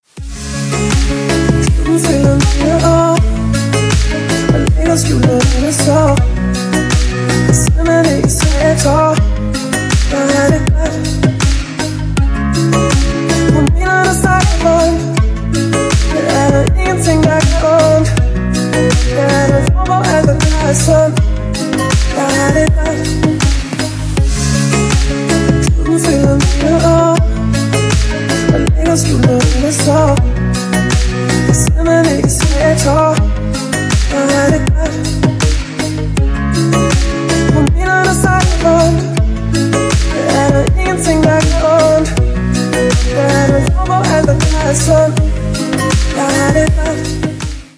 • Качество: 320, Stereo
поп
гитара
спокойные
Красивый рингтон со звуками гитары